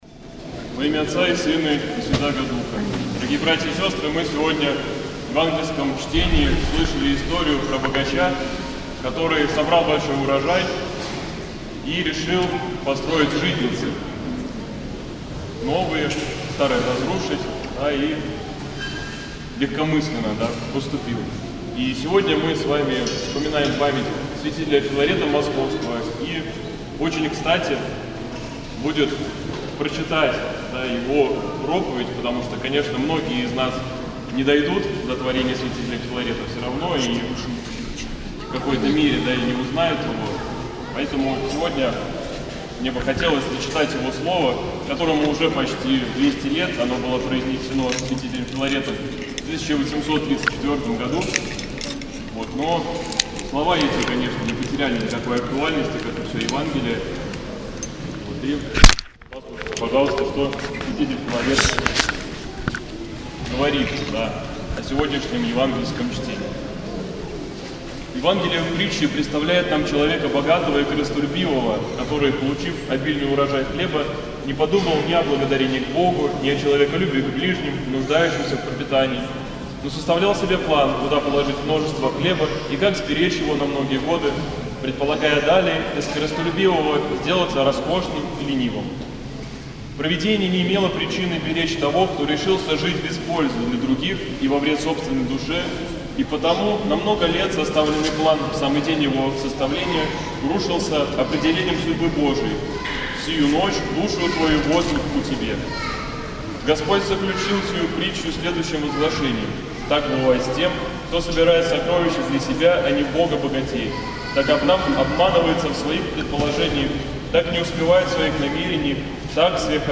НОВОСТИ, Проповеди и лекции
поздняя Литургия